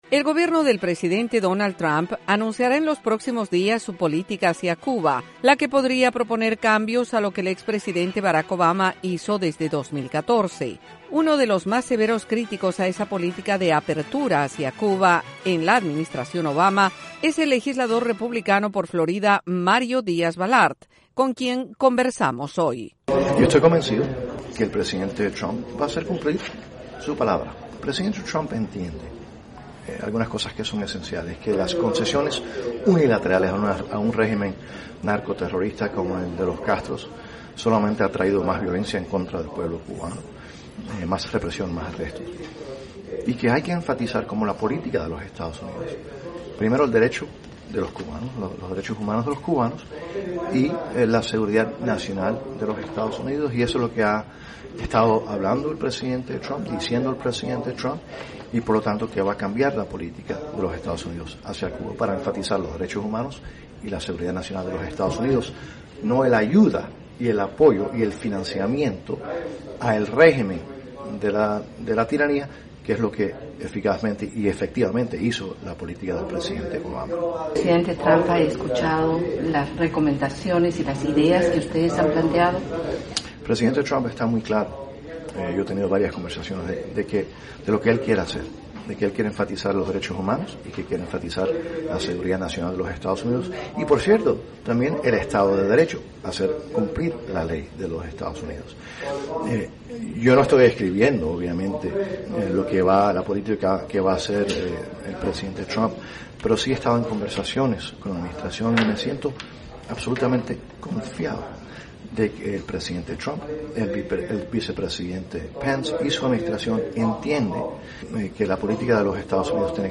El legislador republicano por Florida, Mario Díaz Balart, afirmó en entrevista con la Voz de América que el concepto que se observa en el gobierno del presidente Donald Trump está basado en dos temas principales.